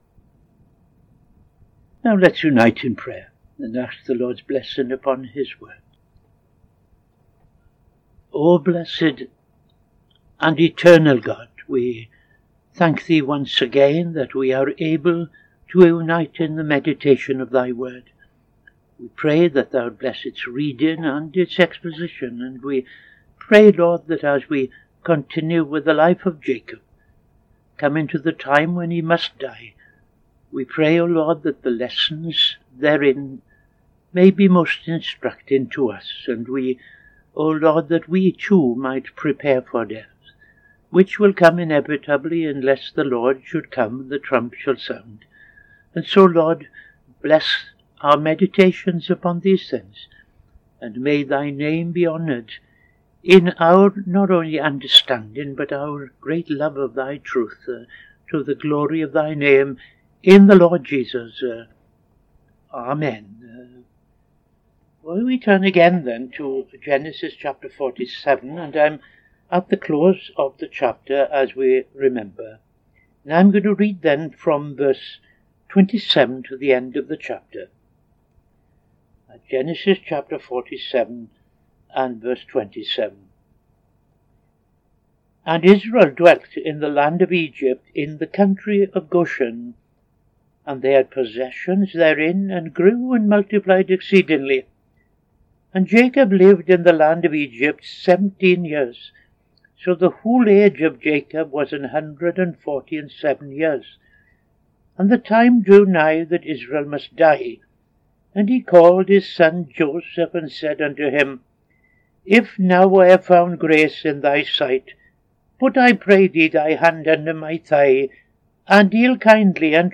Midday Sermon